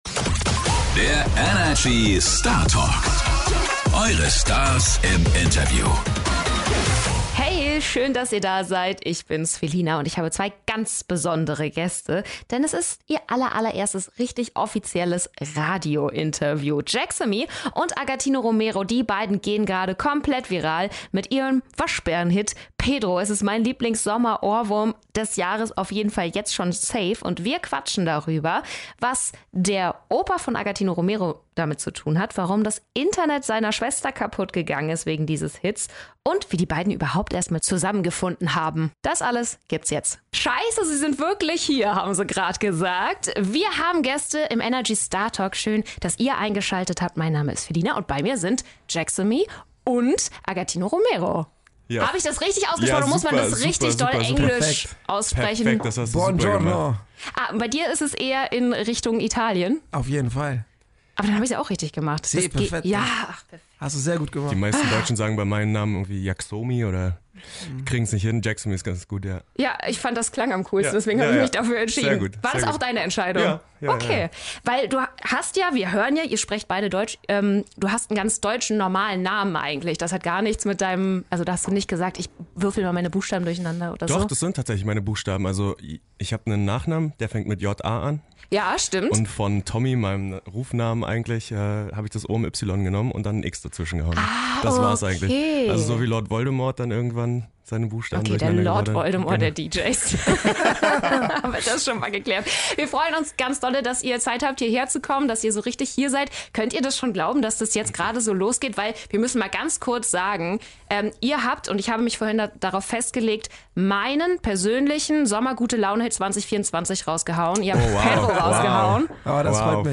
Das haben sie uns alles in ihrem allerersten Radio-Interview erzählt!